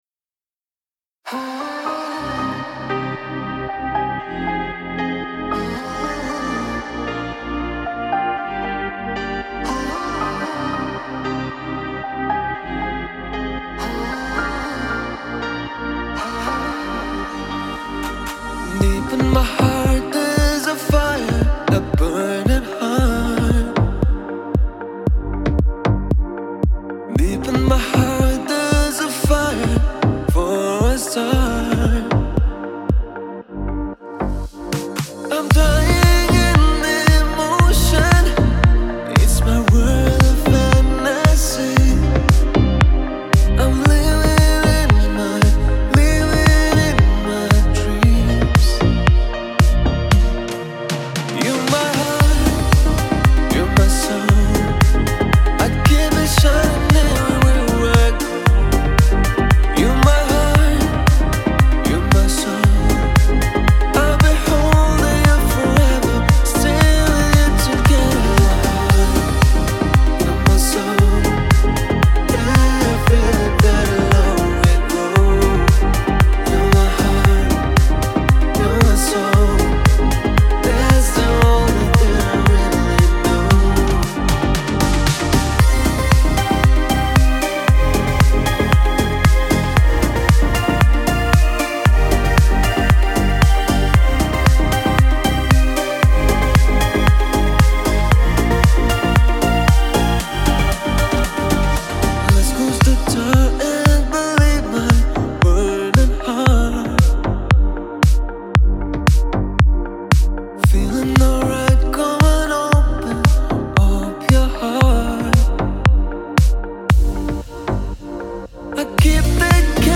pop , dance
Кавер-версия
диско , дуэт